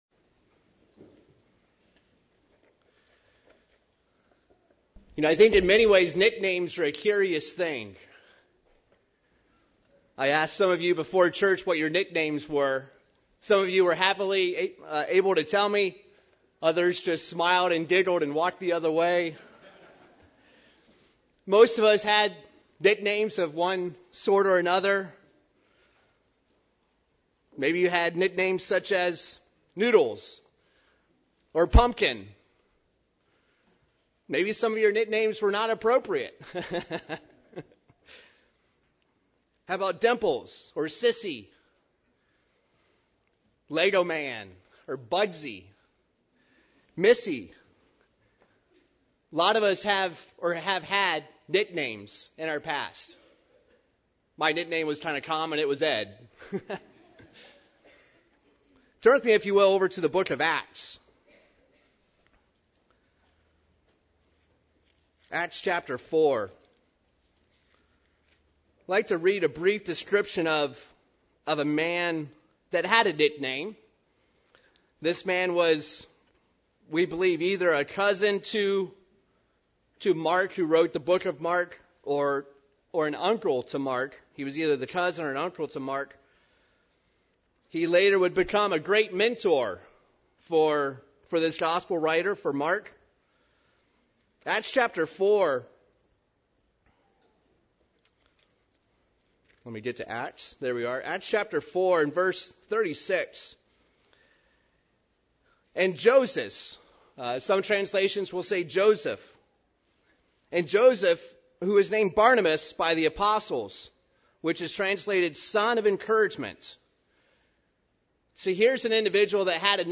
That is why we need to help one another, by being encouragers UCG Sermon Studying the bible?